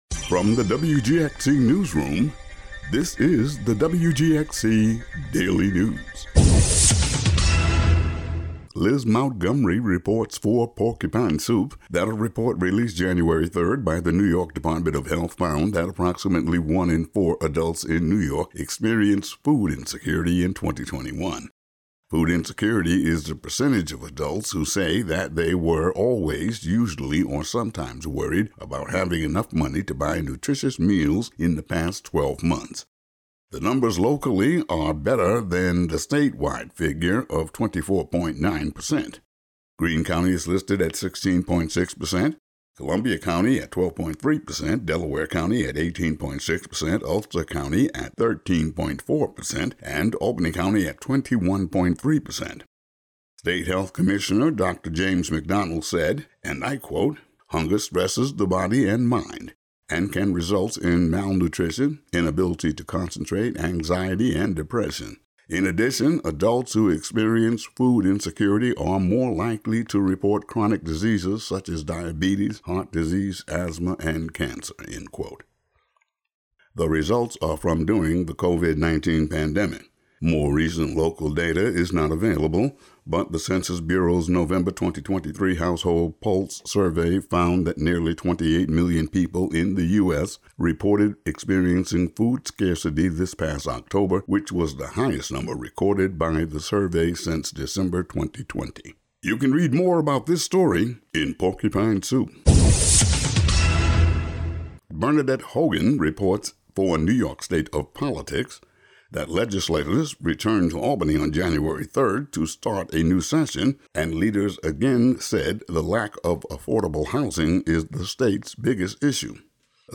Today's audio daily news update.